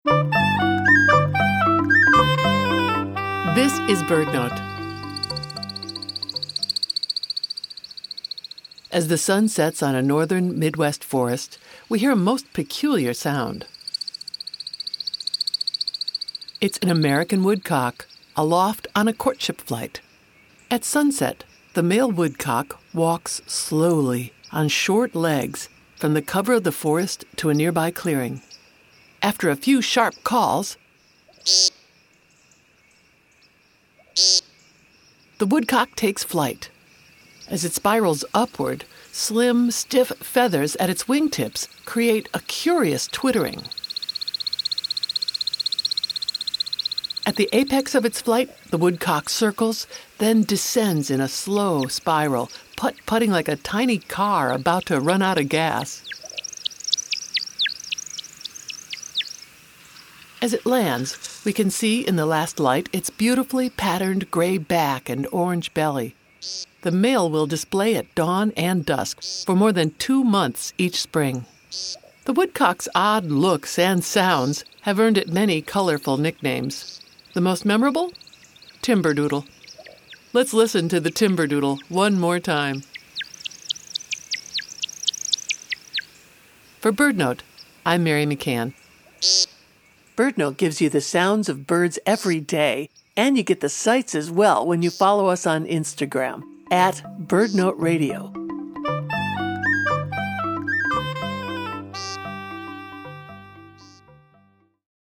At sunset, the male American Woodcock – a plump, robin-sized bird – walks slowly on short legs from the cover of the forest to a nearby clearing. After a few sharp calls, the woodcock takes flight. As it spirals upward, slim, stiff feathers at its wingtips create a curious twittering. At the apex of its flight, the woodcock circles, then descends in a slow spiral, putt-putting like a tiny car about to run out of gas.